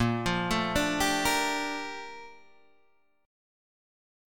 A#M13 chord